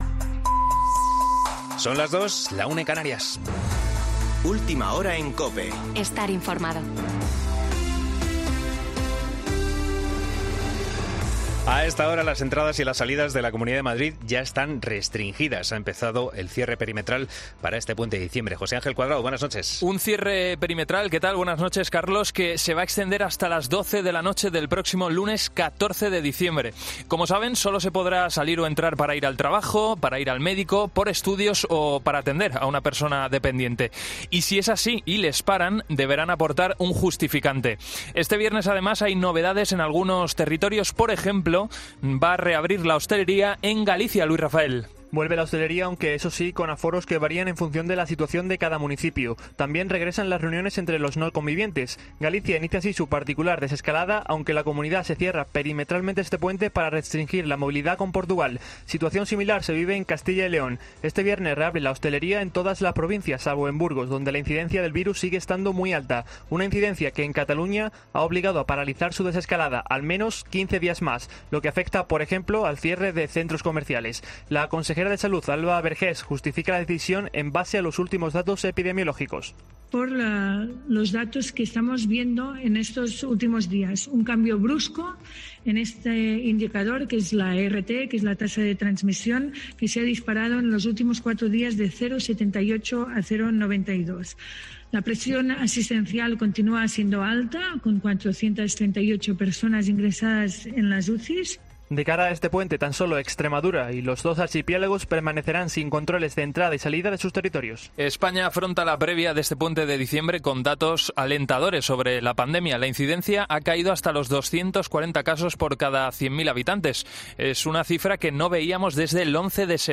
Boletín de noticias COPE del 04 de diciembre de 2020 a las 02.00 horas